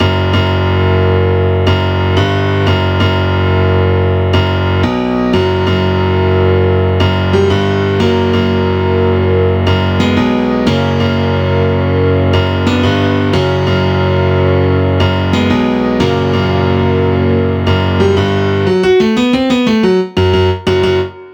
the piano part of the final boss's song